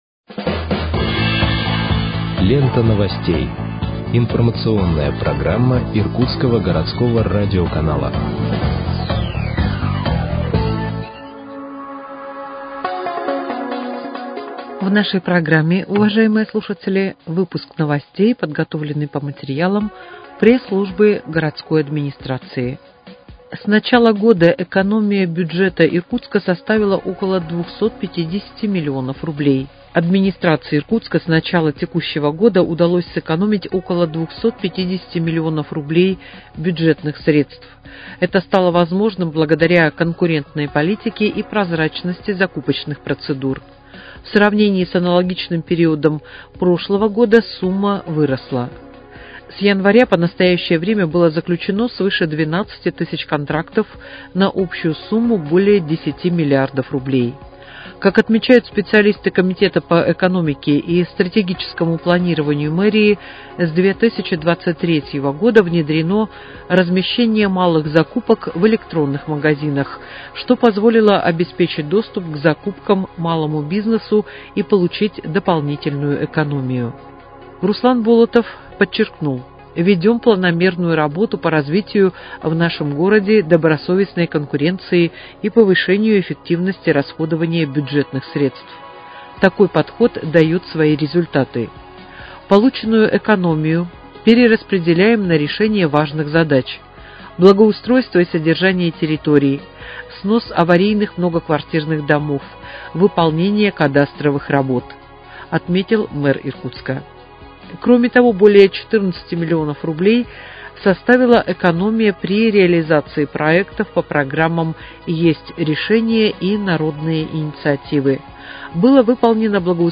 Выпуск новостей в подкастах газеты «Иркутск» от 15.08.2025 № 1